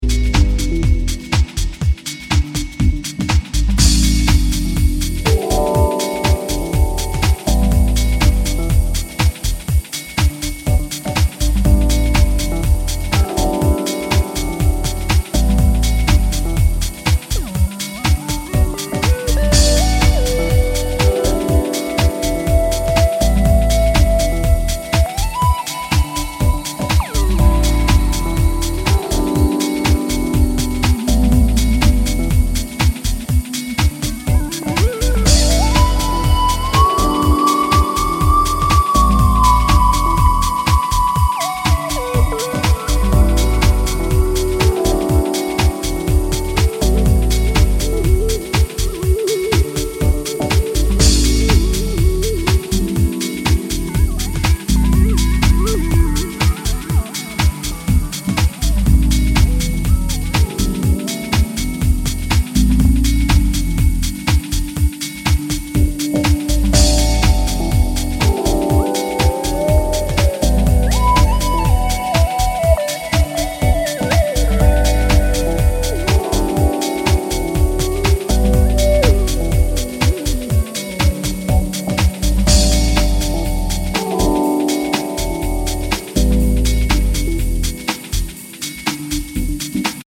deep house
soulful vocals, jazzy harmonies, funky bass lines